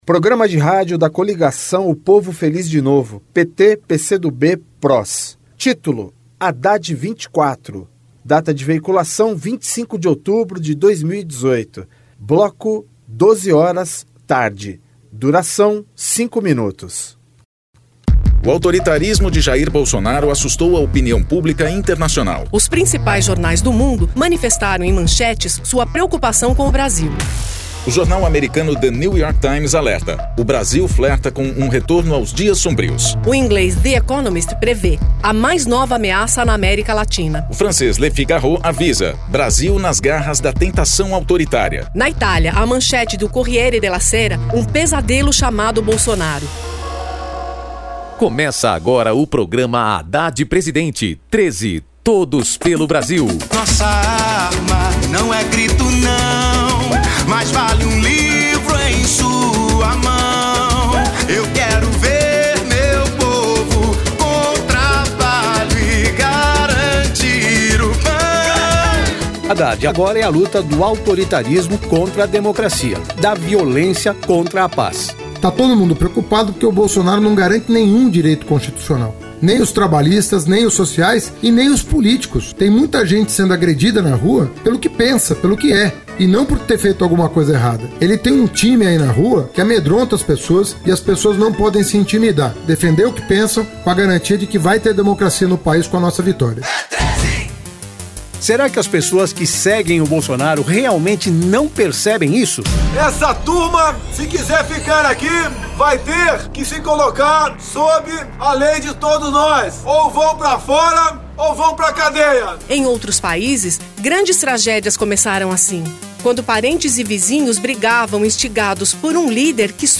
Gênero documentaldocumento sonoro
Descrição Programa de rádio da campanha de 2018 (edição 54), 2º Turno, 25/10/2018, bloco 12hrs.